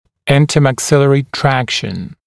[ˌɪntəmæk’sɪlərɪ ‘trækʃn][ˌинтэмэк’силэри ‘трэкшн]межчелюстная тяга